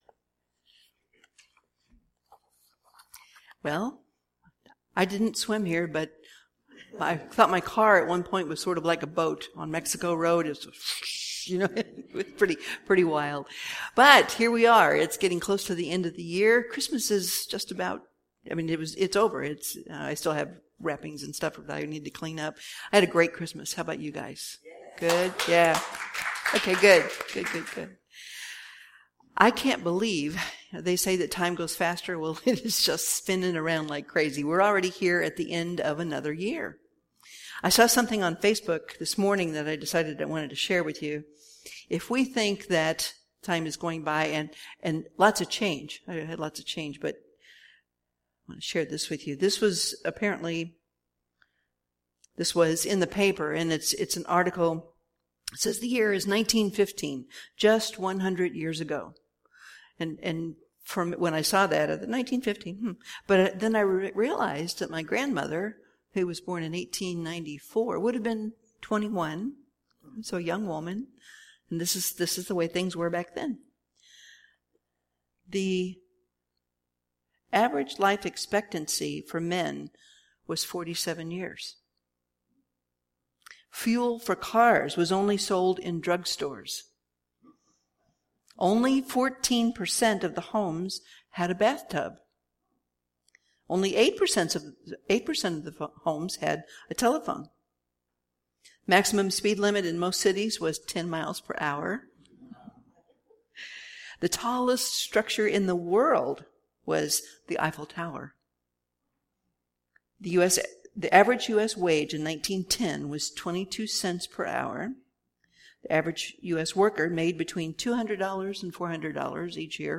Series: Sermons 2015